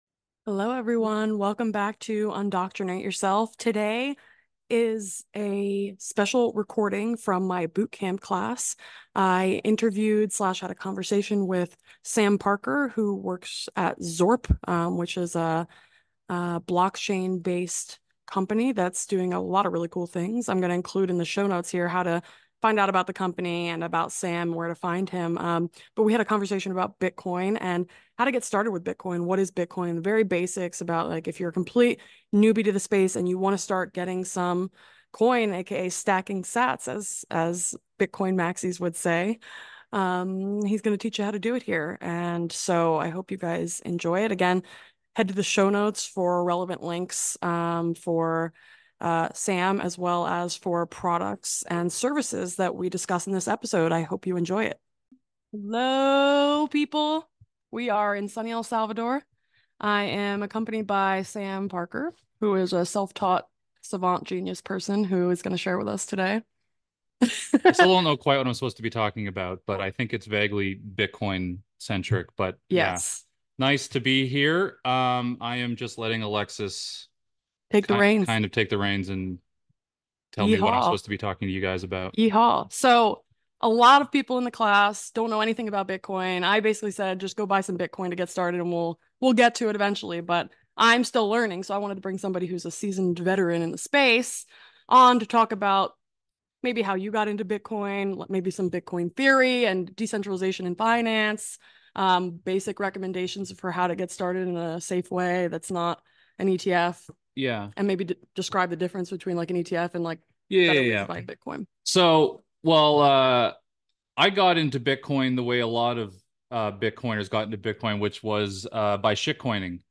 This episode is a guest lecture on bitcoin from my Bootcamp Reboot course.